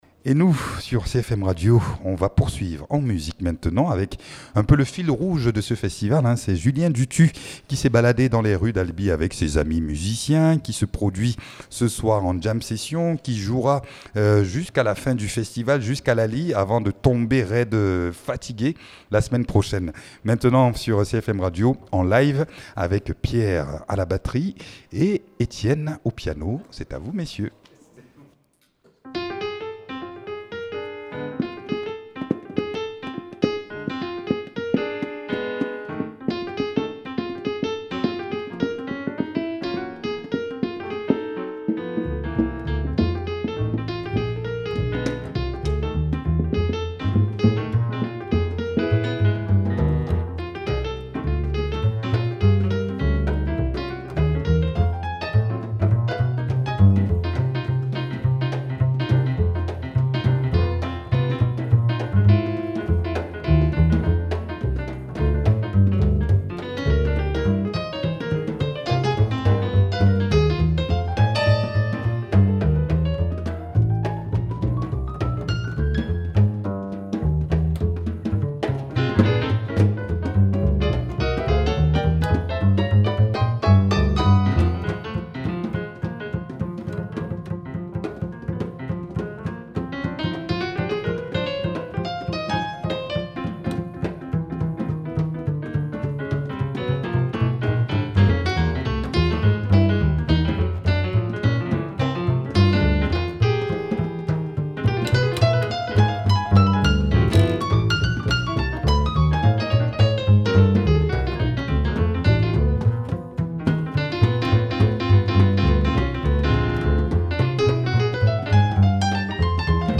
à la contrebasse
au piano
à la batterie